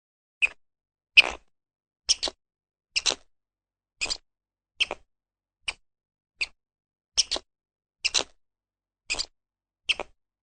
Karty aktywności - głos wiewiórki - EDURANGA
ka1_k_24_wiewiorka.mp3